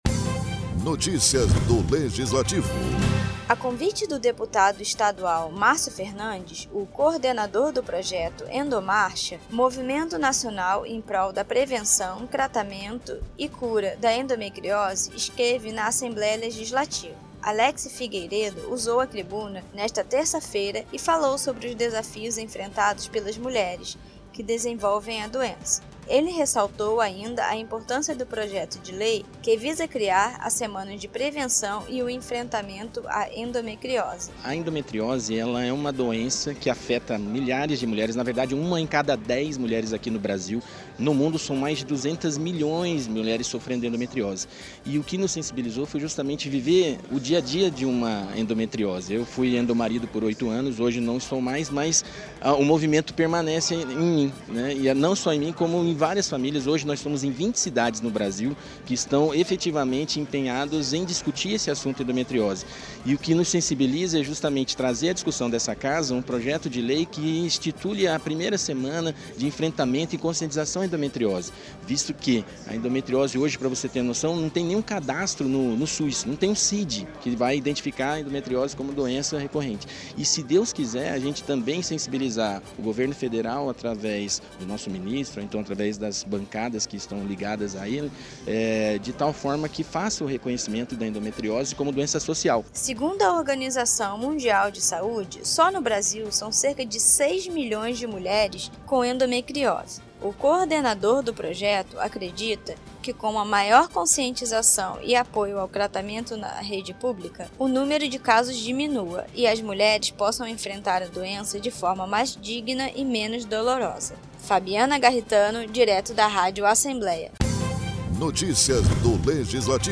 Endometriose é tema de discurso na tribuna da Casa de Leis